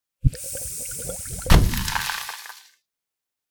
acid-splash-003-90ft.ogg